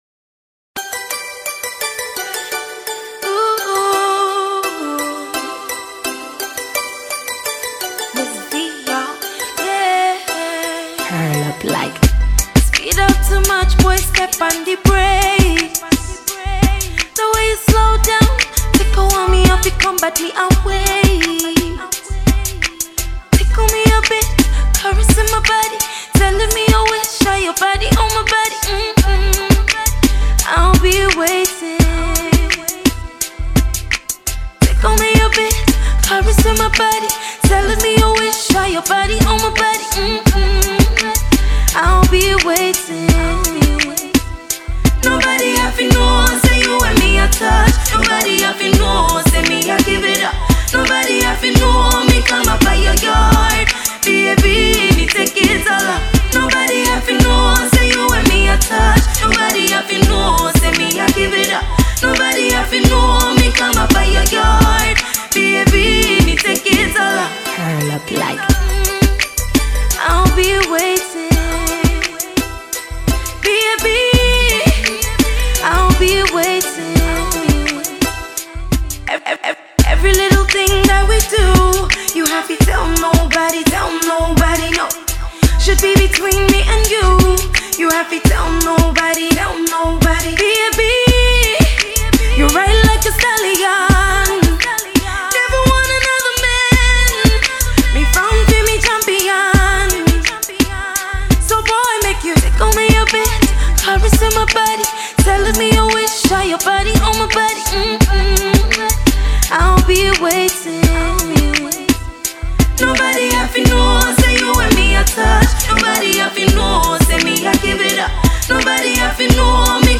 Ghanaian Songstress